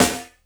50SD  01  -R.wav